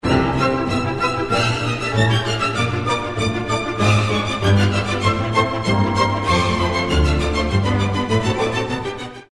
cartoon